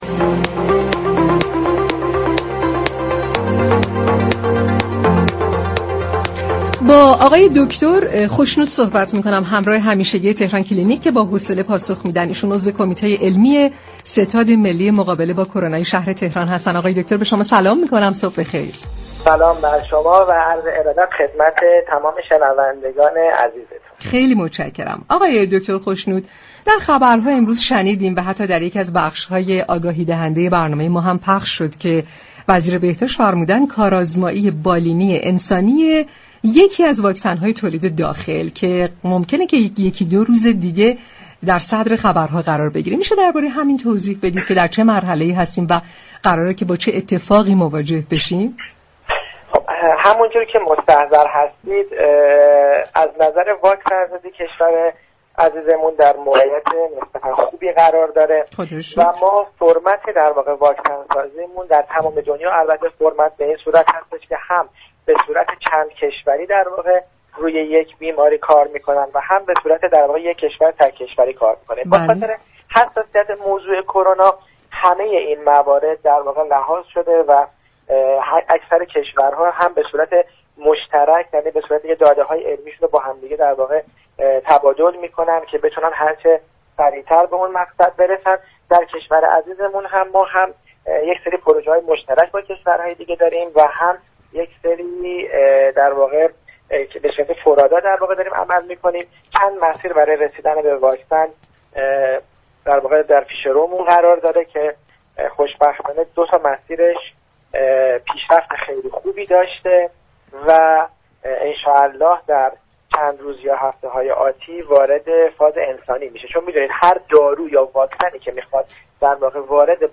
در گفتگو با برنامه تهران كلینیك درباره كارآزمایی بالینی یكی از واكسن های ایرانی كرونا